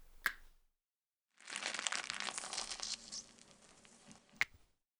administer_iv_02.ogg